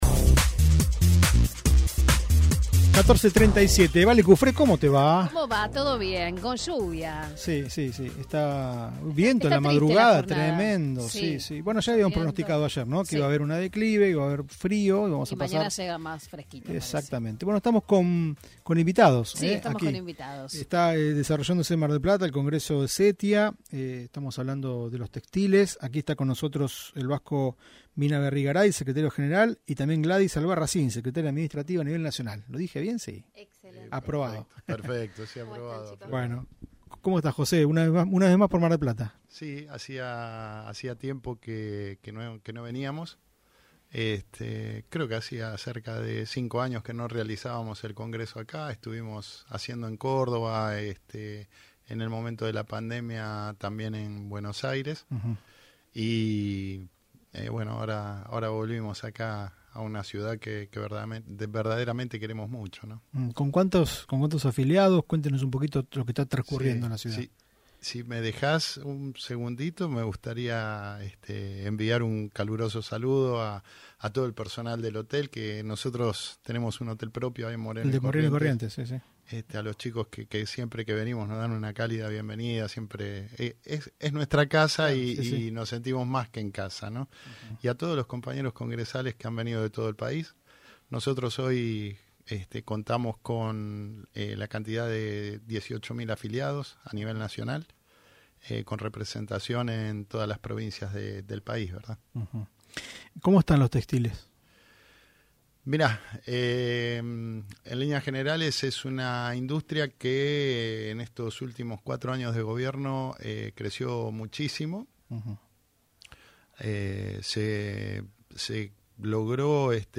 en diálogo con "UPM" de Mitre (FM. 103.7).